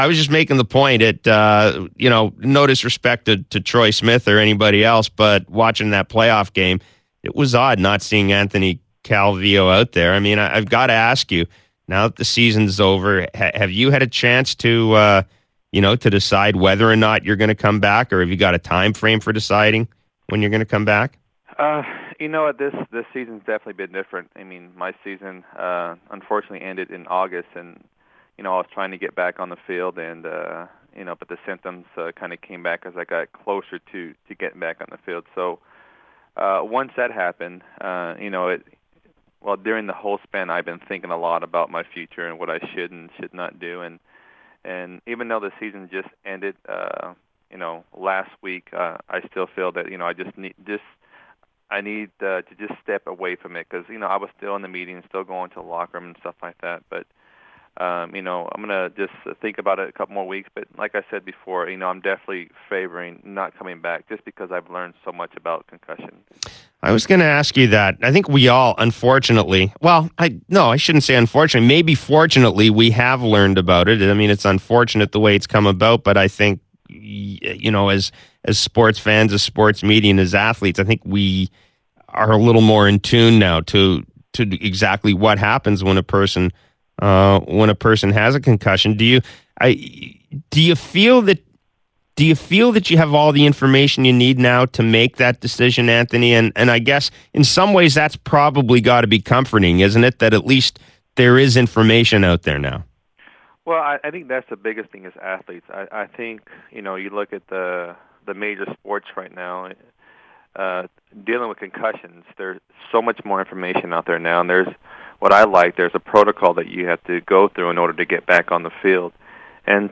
Calvillo on Jeff Blair Show: The 41-year-old was only able to play in seven games in 2013 after suffering a concussion when his head was slammed into the turf on a hit against the Saskatchewan Roughriders on Aug. 17.